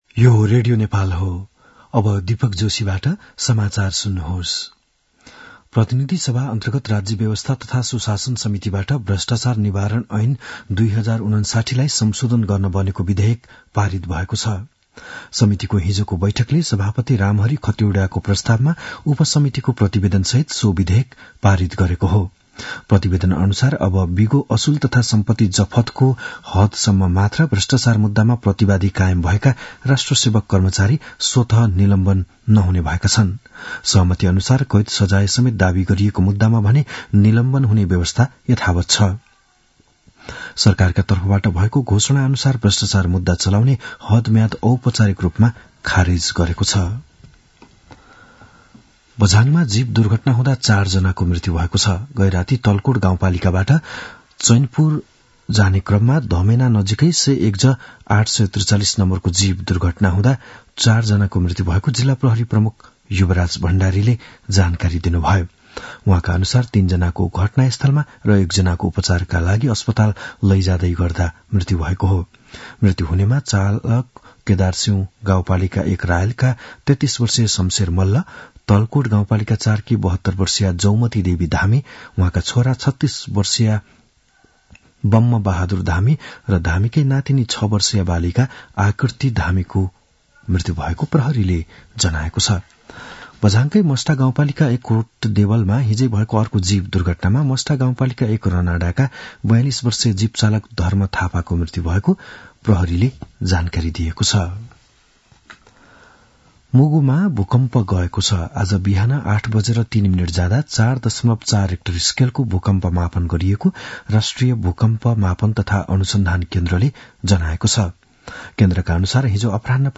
बिहान ११ बजेको नेपाली समाचार : २० पुष , २०८१
11-am-nepali-news-1-2.mp3